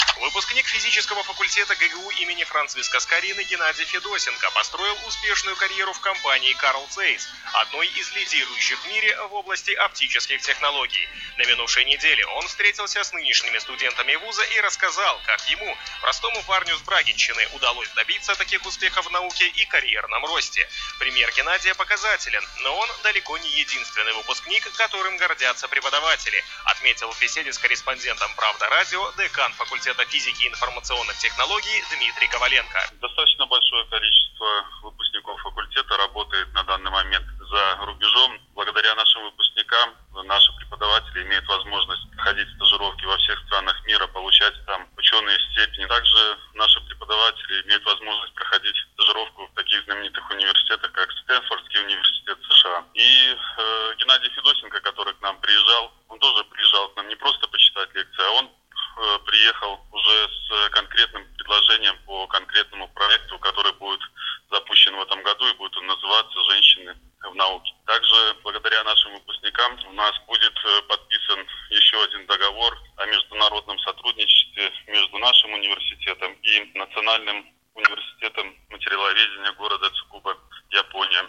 Интервью на "Правда радио" PDF Печать E-mail